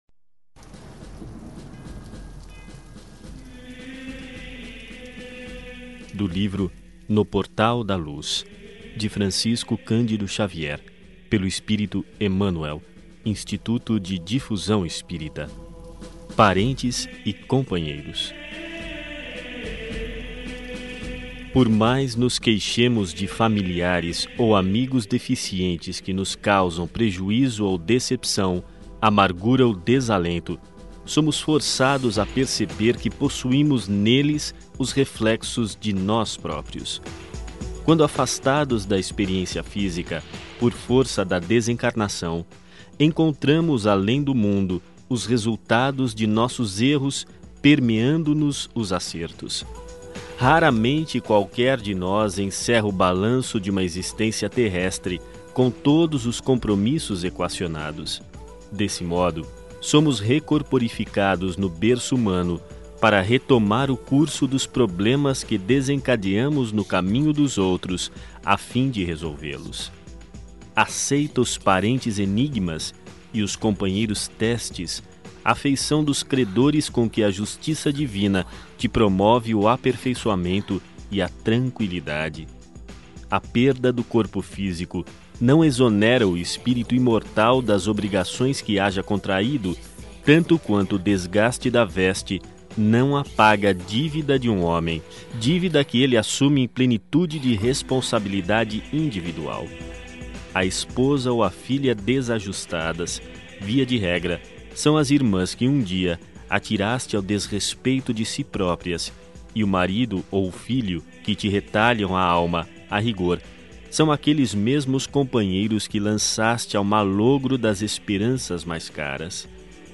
Ouça outras mensagens na voz de Chico Xavier Clicando aqui